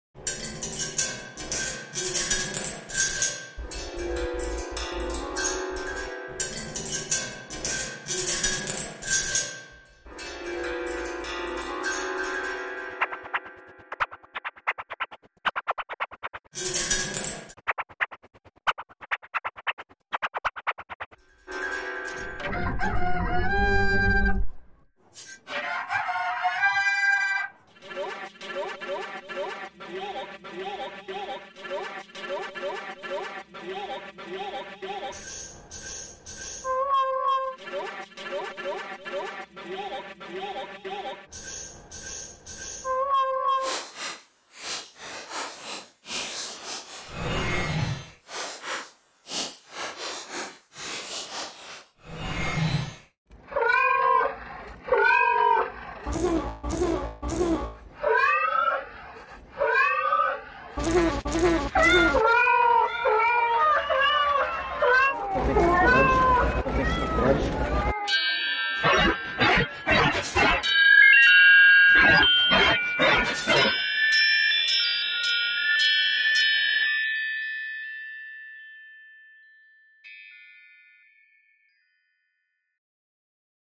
which emits animal noises when coins are dropped into it